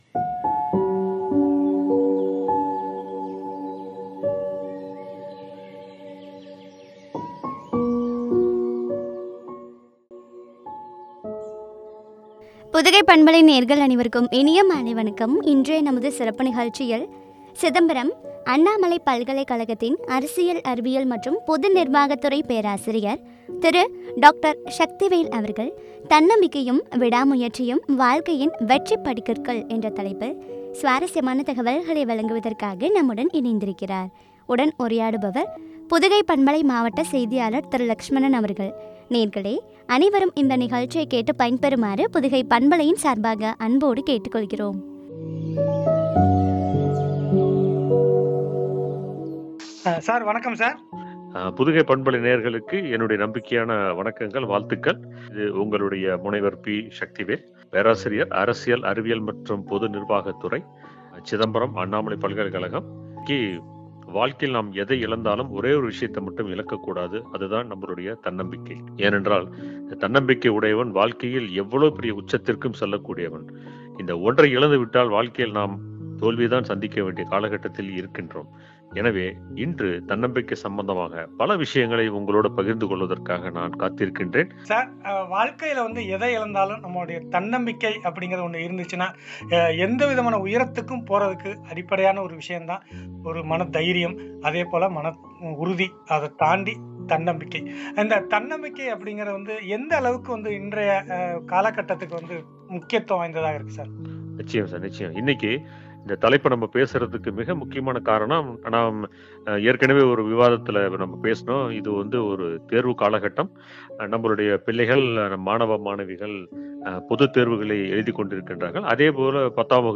விடாமுயற்சியும் வாழ்க்கையின் வெற்றிபடிக்கட்டுகள் என்ற தலைப்பில் வழங்கிய உரையாடல்.